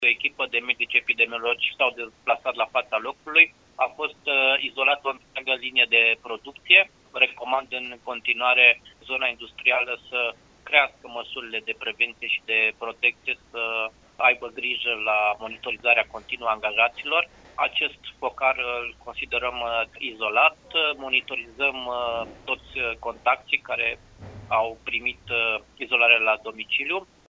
Şeful DSP, Horea Timiș, spune că toţi angajaţii contacţi direcţi ai celor bolnavi sunt în izolare la domiciliu.